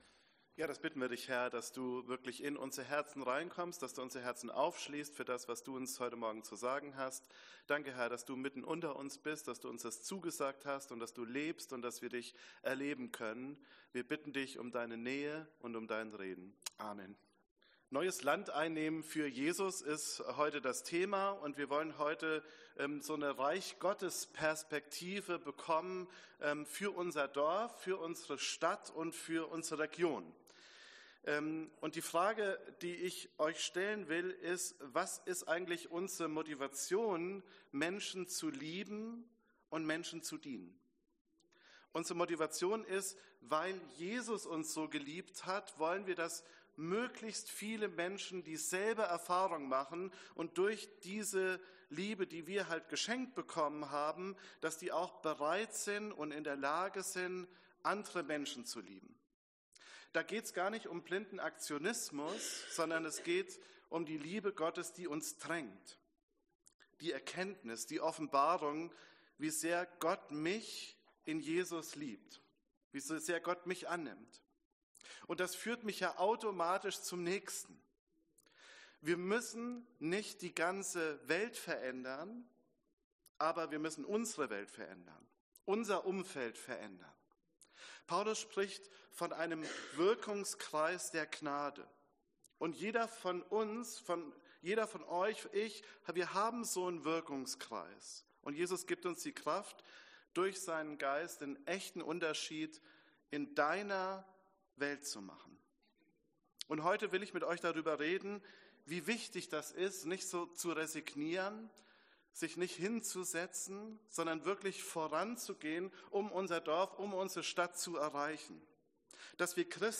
Predigt C1 - Christus zuerst, eins in Christus